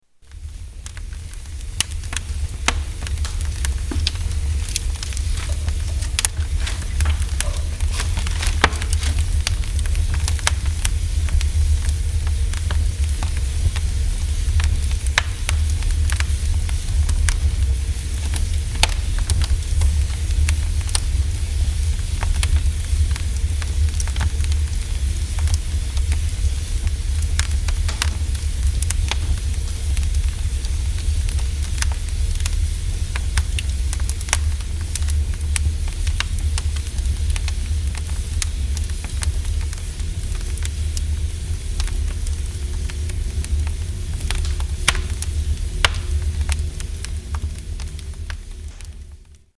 Sounds of the boiler room
Tags: Travel Latvia Europe Baltic Sea Sounds of Latvia